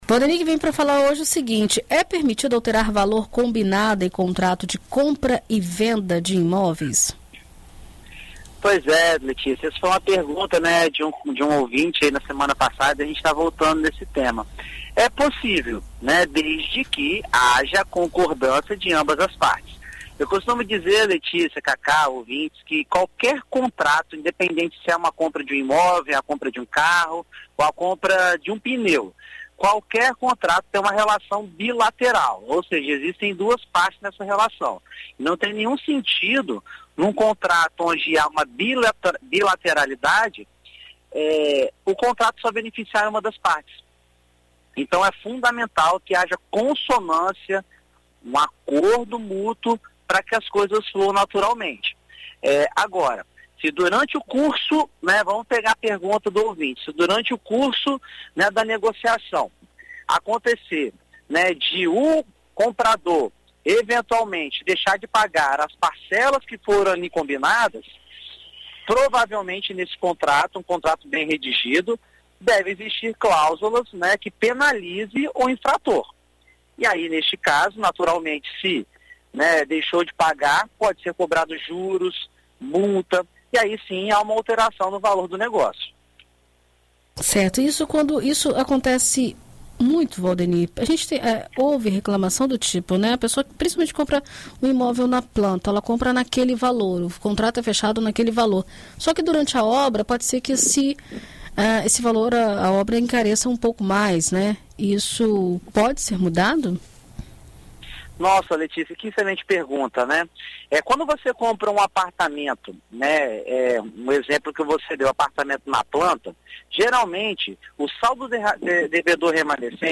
Na coluna Seu Imóvel desta terça-feira (22), na BandNews FM Espírito Santo